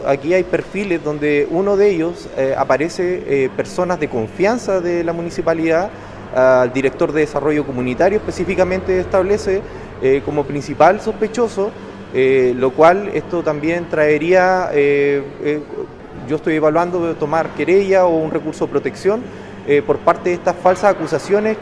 De esta manera lo relató el concejal Jara.